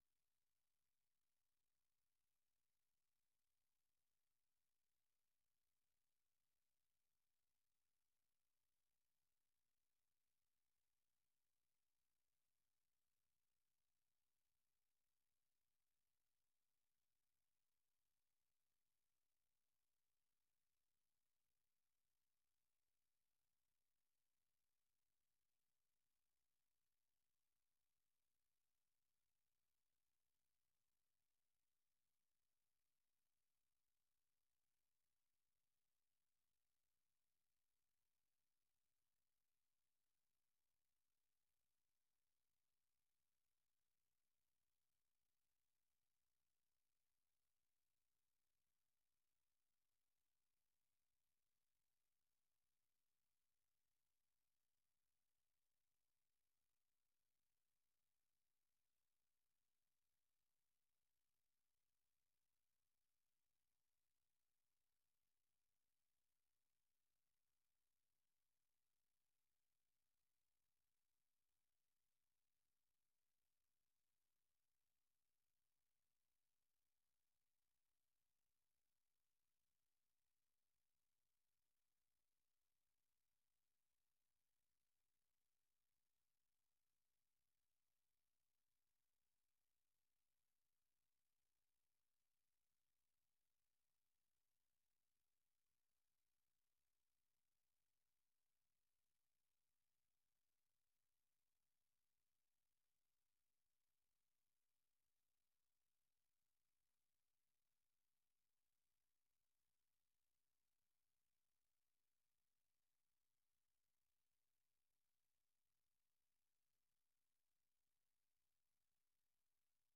The URL has been copied to your clipboard 分享到臉書 分享到推特網 No media source currently available 0:00 0:30:00 0:00 下載 128 kbps | MP3 64 kbps | MP3 時事經緯 時事經緯 分享 時事經緯 分享到 美國之音《時事經緯》每日以30分鐘的時間報導中港台與世界各地的重要新聞，內容包括十分鐘簡短國際新聞，之後播出從來自世界各地的美國之音記者每日發來的採訪或分析報導，無論發生的大事與你的距離是遠還是近，都可以令你掌握與跟貼每日世界各地發生的大事！